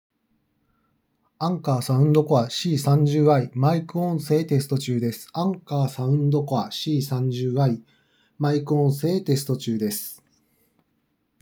✅「Anker Soundcore C30i」のマイクテスト
「Anker Soundcore C30i」の方が少しだけクリアな印象。その分少しだけ荒さはあるが聞きやすさもある。